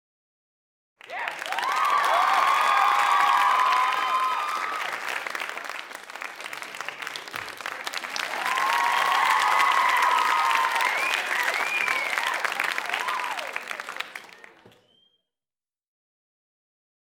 Download Crowds sound effect for free.
Crowds